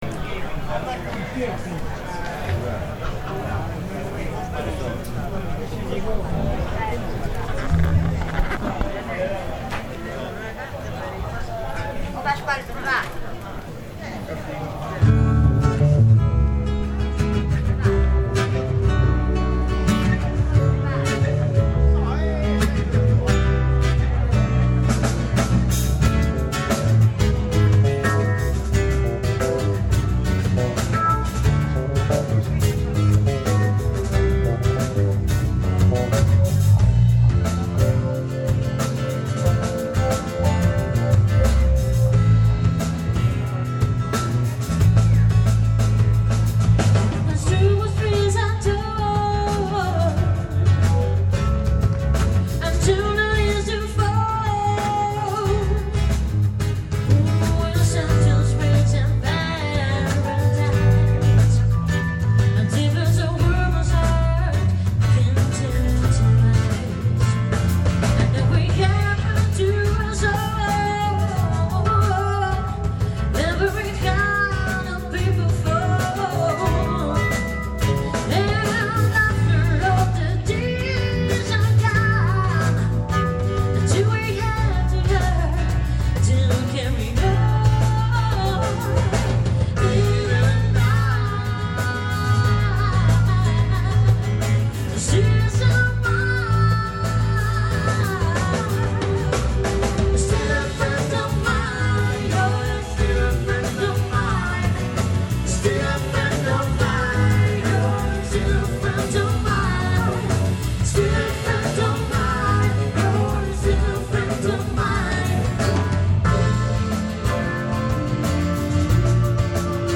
ascoltando l’audio del brano di apertura della manifestazione.
Borgo-In-Jazz-2020-Apertura.mp3